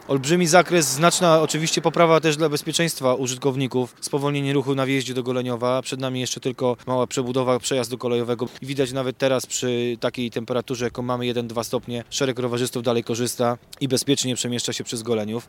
Wymieniono w niej całą infrastrukturę podziemną, na całej jej długości wybudowana została ścieżka rowerowa, pojawiło się nowe oświetlenie i nowe miejsca parkingowe – Jesteśmy bardzo zadowoleni z efektów, szczególnie, że na tej ruchliwej drodze bezpieczniej czuć się mogą nie tylko właściciele samochodów – mówi starosta Tomasz Kulinicz.